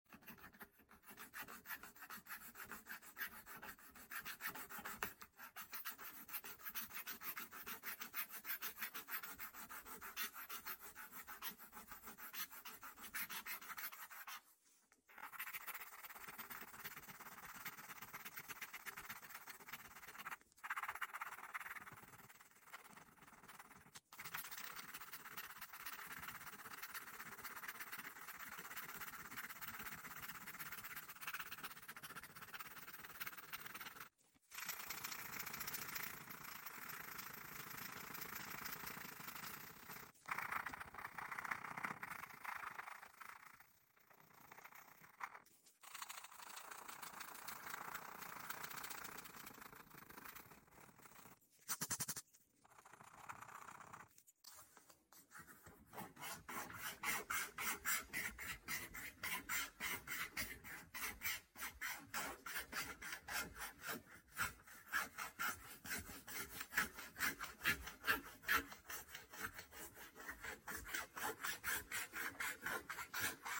Kratzen ASMR Was gefällt sound effects free download